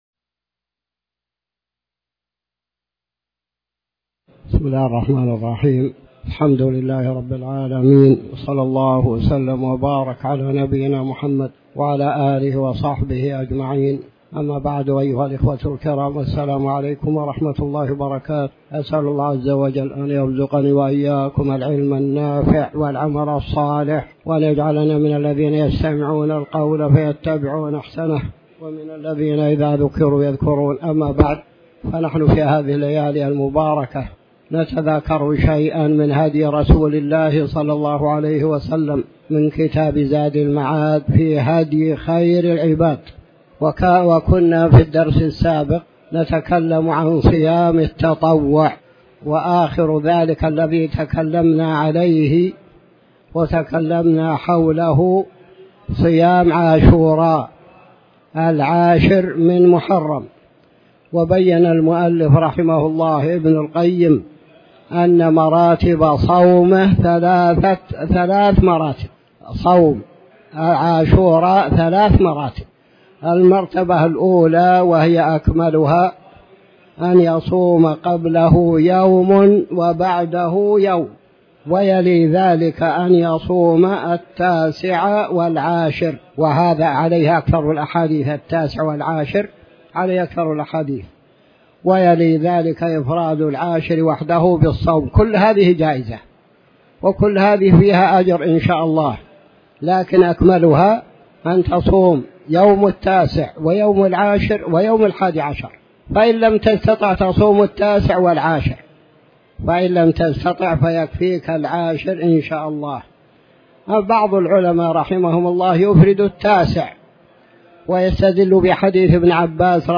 تاريخ النشر ٢٠ محرم ١٤٤٠ هـ المكان: المسجد الحرام الشيخ